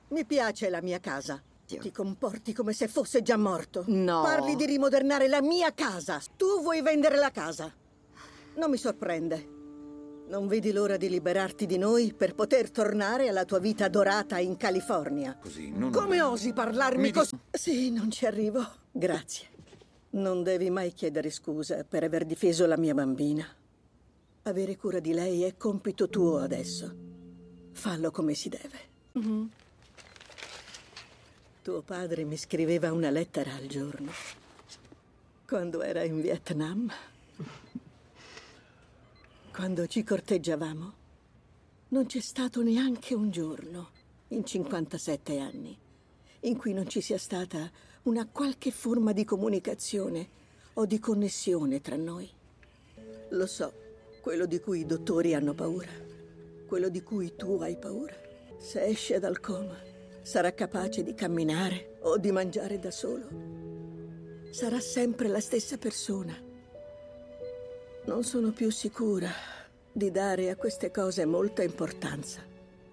nel telefilm "9-1-1", in cui doppia Beverly Todd.